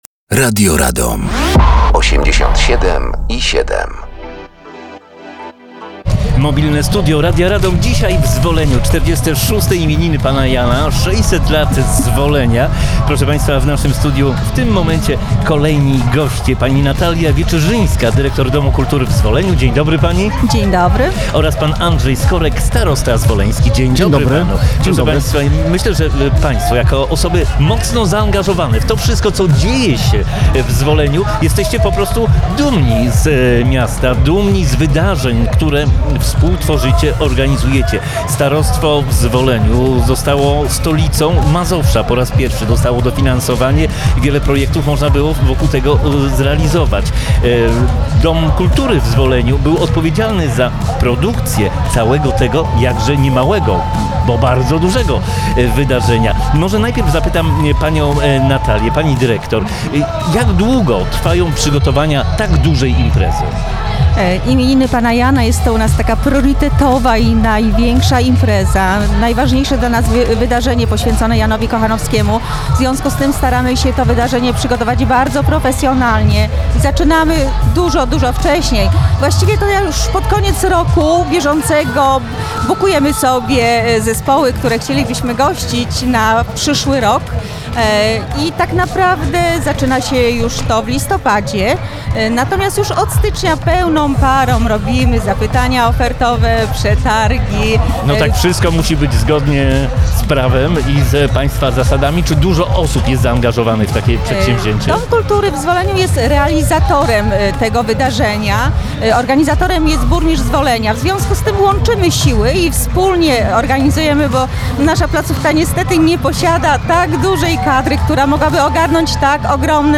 Mobilne Studio Radia Radom gościło w Zwoleniu podczas finałowego koncertu 46 Urodzin Pana Jana.